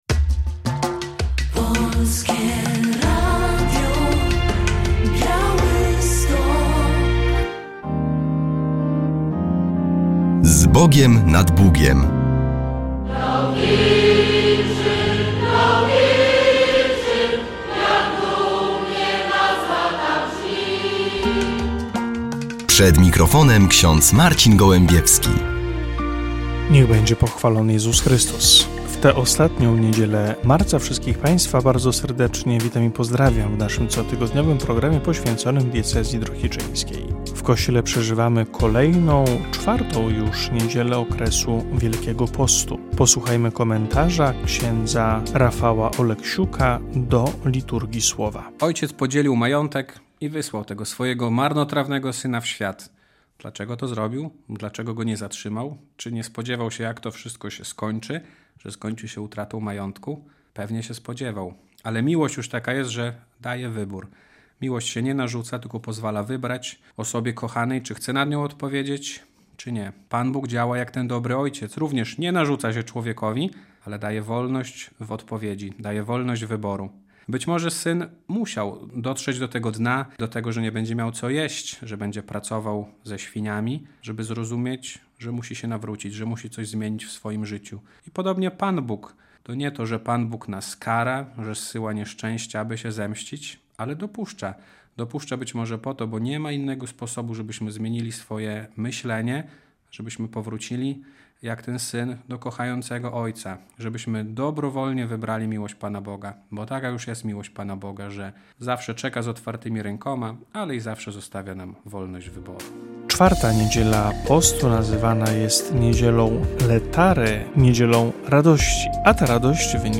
W audycji relacja z jubileuszowego nabożeństwa w intencji poszanowania i świętości życia.